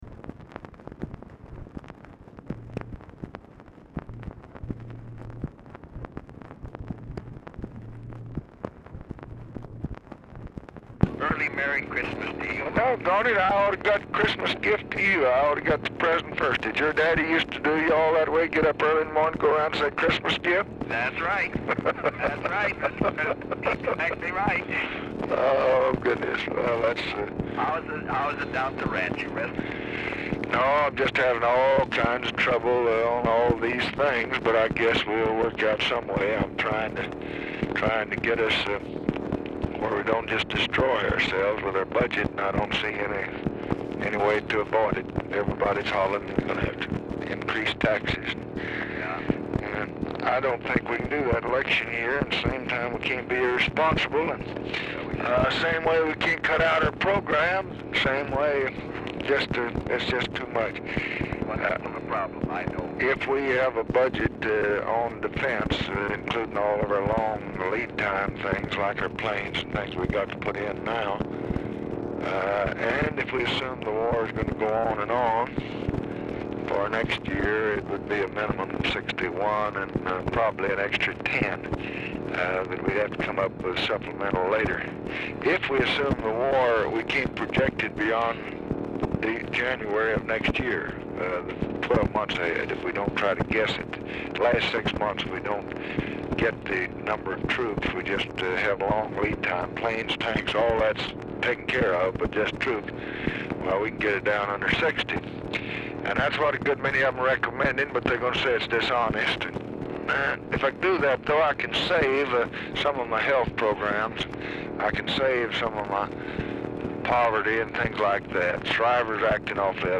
RECORDING STARTS AFTER CONVERSATION HAS BEGUN
Format Dictation belt
Location Of Speaker 1 LBJ Ranch, near Stonewall, Texas
Specific Item Type Telephone conversation